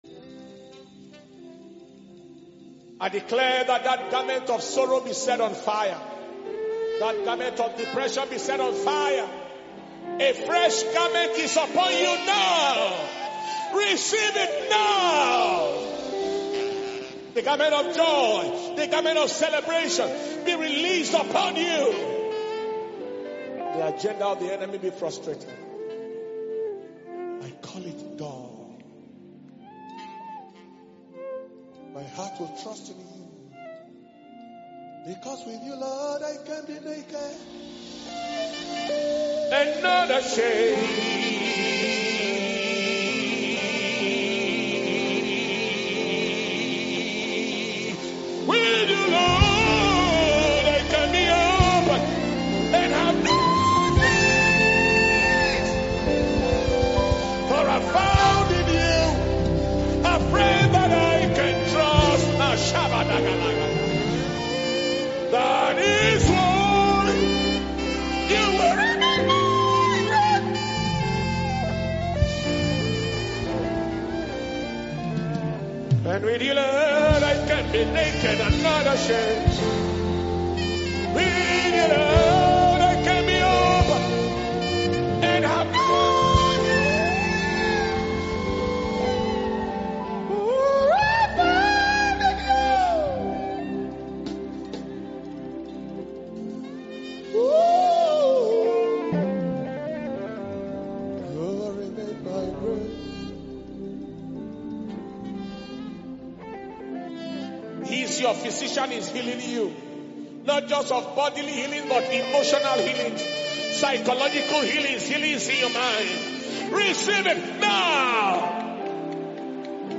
October 11th 2020 Holy Ghost Service.